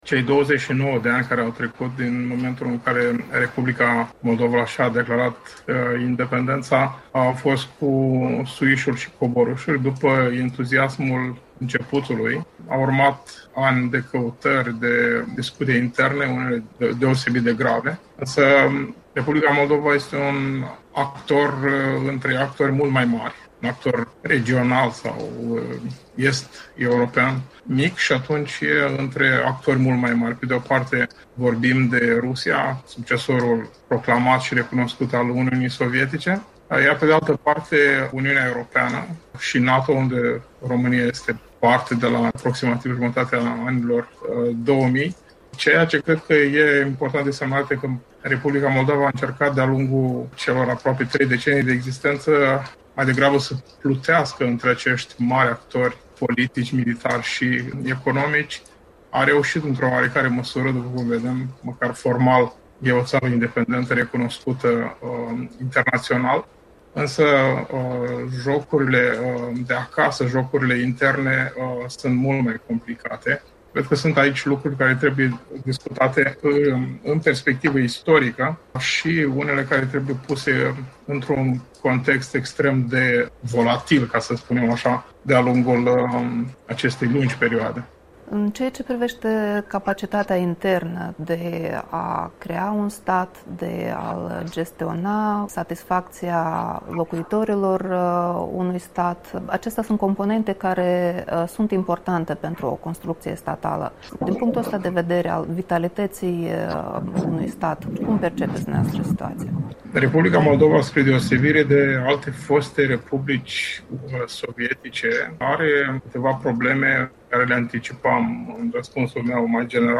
Am stat de vorbă